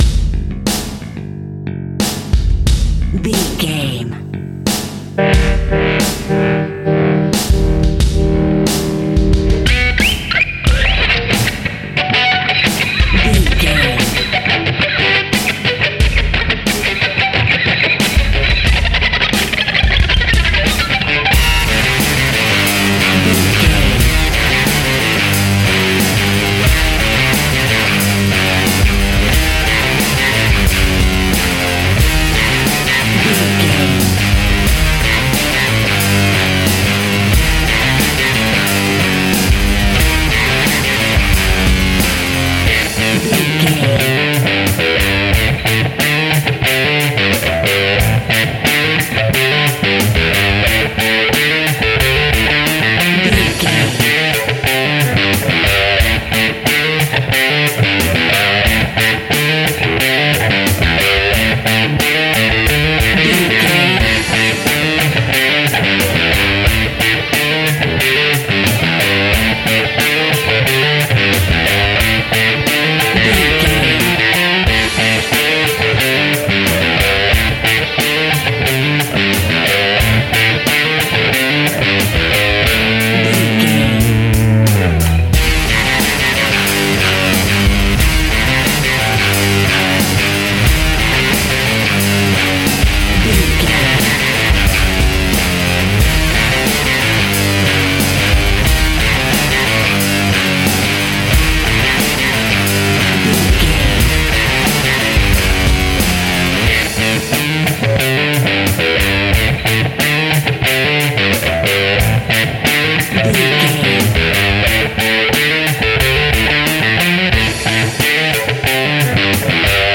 Epic / Action
Fast paced
Aeolian/Minor
Grunge
hard rock
overdrive
distortion
hard rock instrumentals
heavy guitars
Rock Drums
Rock Bass